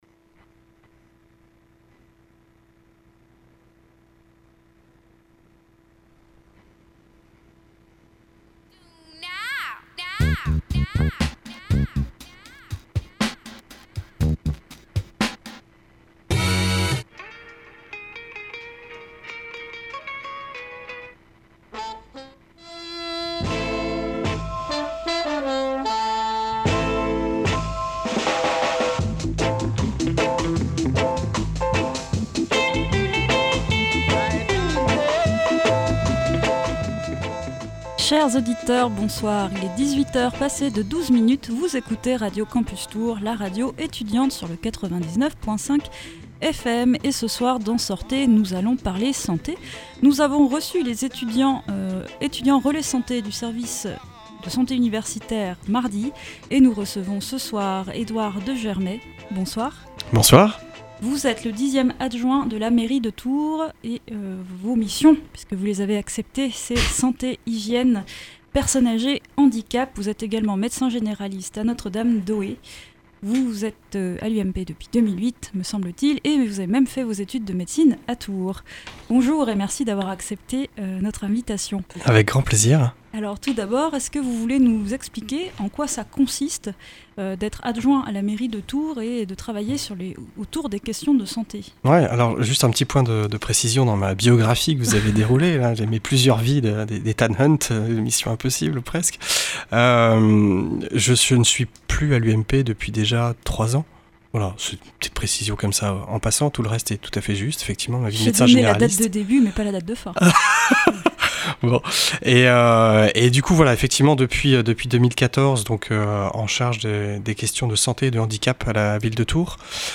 Nous recevons Edouard de Germay, adjoint à la mairie de Tours, en charge des questions de santé, hygiène, personne âgée et handicap. Il nous présente les jeudis de la santé, une initiative portée par la ville de Tours, la Faculté de Médecine de l’Université de Tours, le CHRU et la Mutualité Française Centre-Val de Loire.